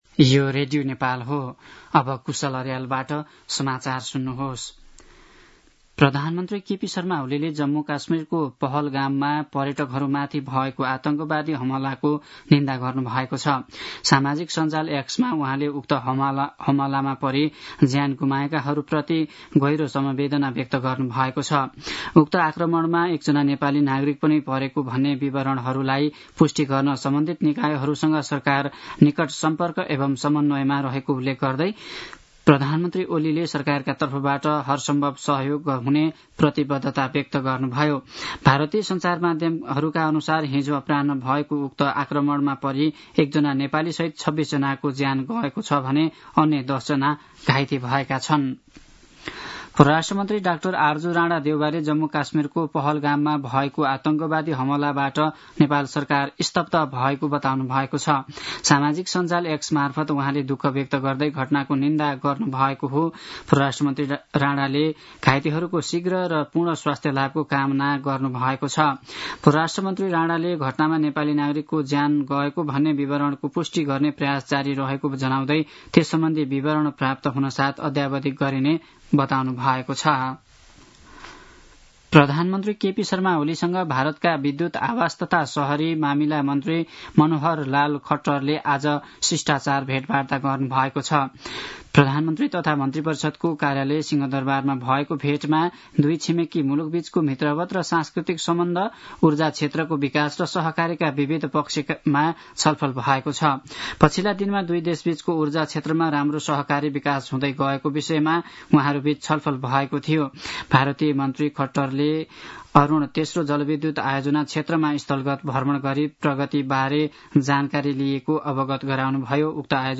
दिउँसो ४ बजेको नेपाली समाचार : १० वैशाख , २०८२
4-pm-news-1-8.mp3